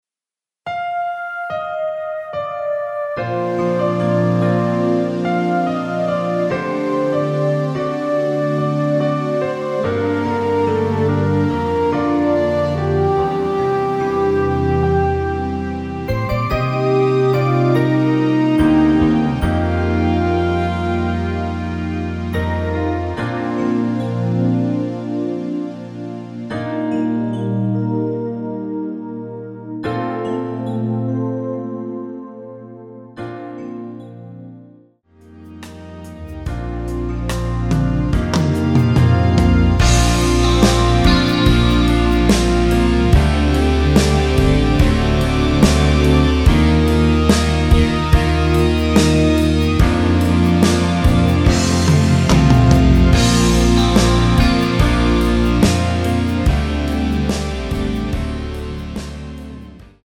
Bb
◈ 곡명 옆 (-1)은 반음 내림, (+1)은 반음 올림 입니다.
앞부분30초, 뒷부분30초씩 편집해서 올려 드리고 있습니다.